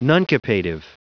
Prononciation du mot nuncupative en anglais (fichier audio)
Prononciation du mot : nuncupative